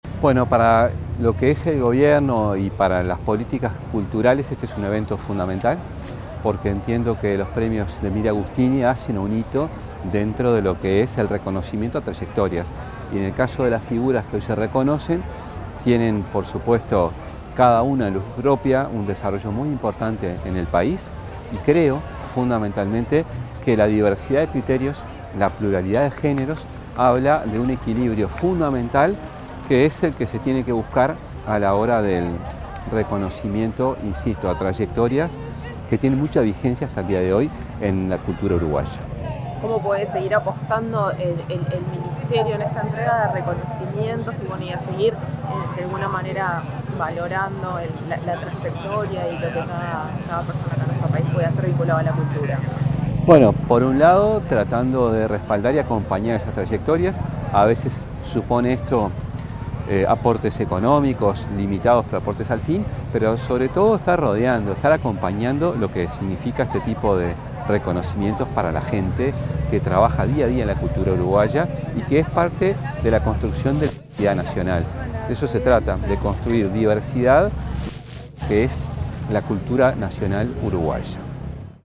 Declaraciones del ministro de Cultura, José Carlos Mahía
Antes de participar en la entrega de premios Delmira Agustina 2025, el ministro de Educación y Cultura (MEC), José Carlos Mahía, dialogó con la prensa